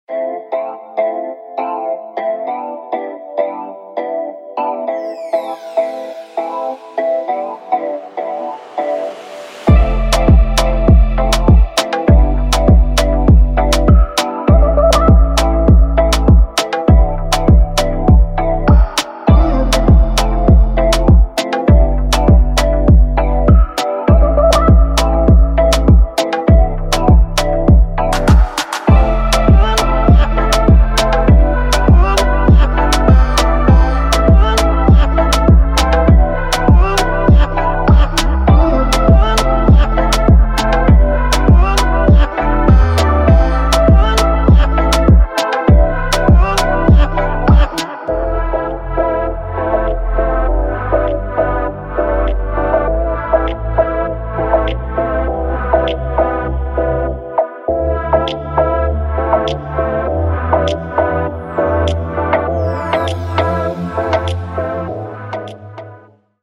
Looking for a fire Afrobeat instrumental?
blends rich melodies with deep Afro-fusion vibes.
soulful, rhythmic, and ready for any hit.